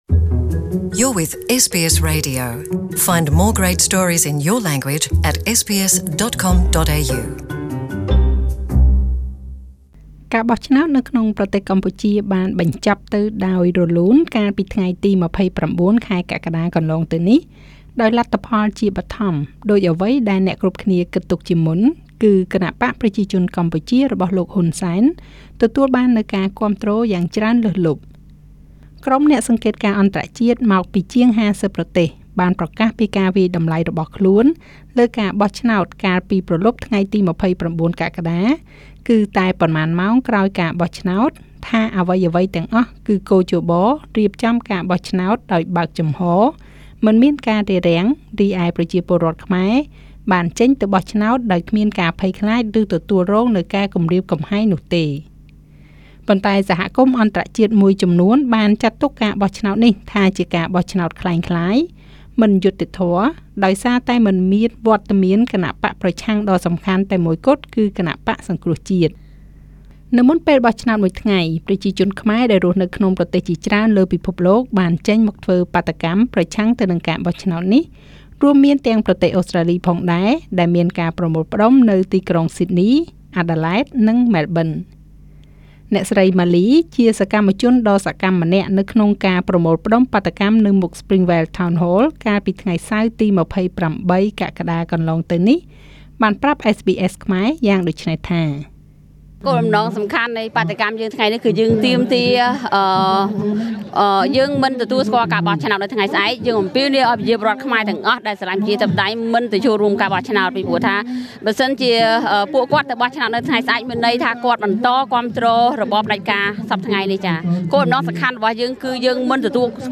ជាបន្តទៅនេះជាមតិយោបល់របស់អ្នកចូលរួមនារសៀលថ្ងៃនោះ៖